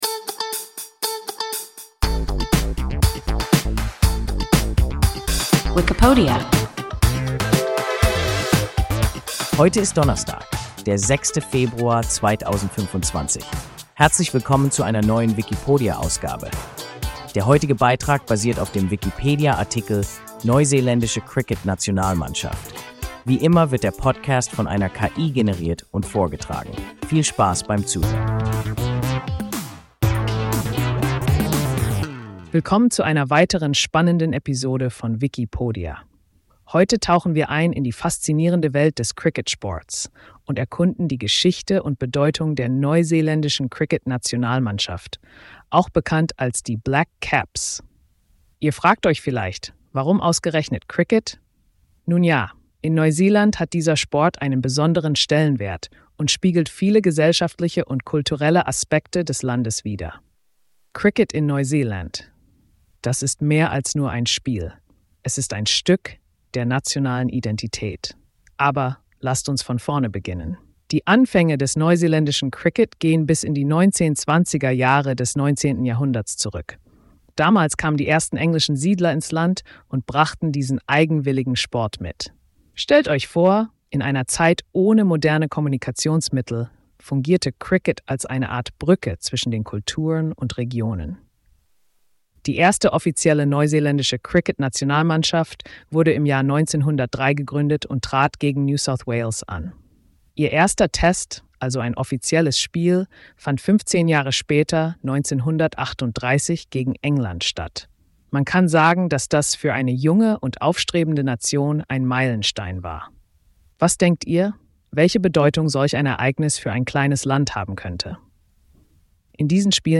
Neuseeländische Cricket-Nationalmannschaft – WIKIPODIA – ein KI Podcast